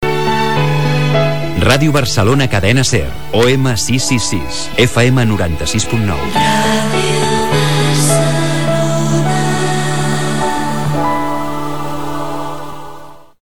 Identificació i freqüències de l'emissora en OM i FM